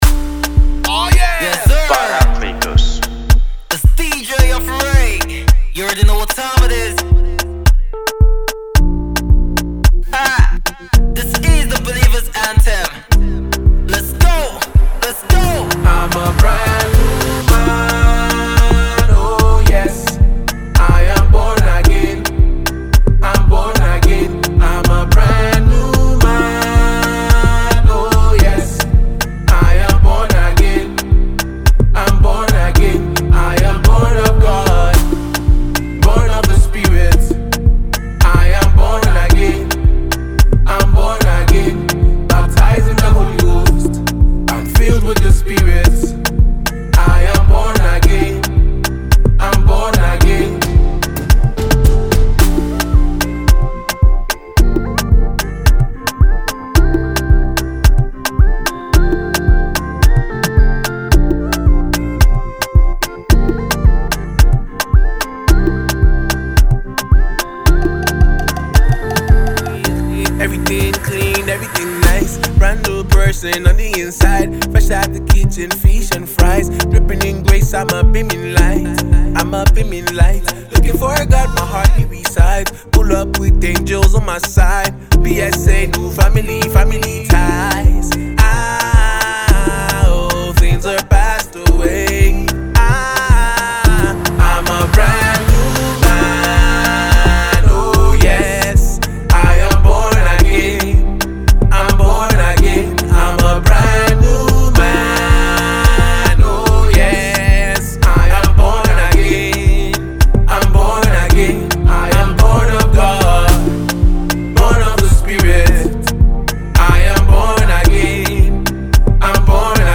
Afrogospel
Urban gospel
Scheduled to drop on Good Friday, this soul-stirring tra…